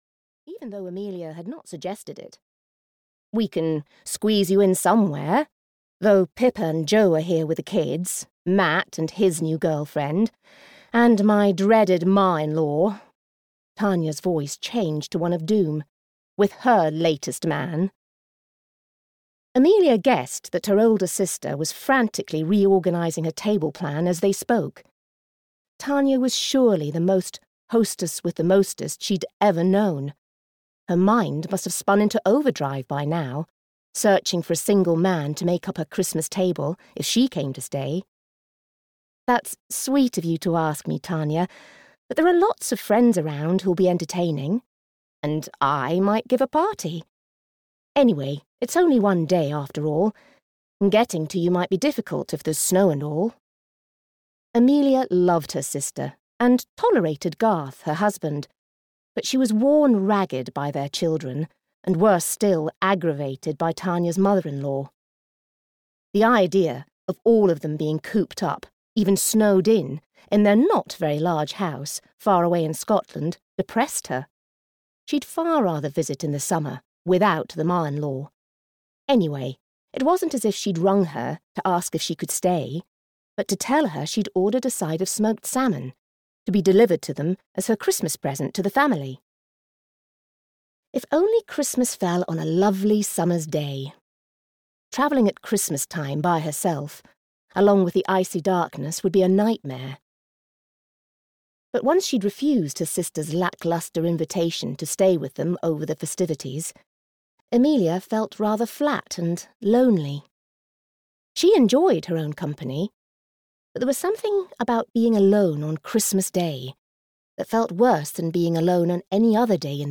The Christmas Menagerie (EN) audiokniha
Ukázka z knihy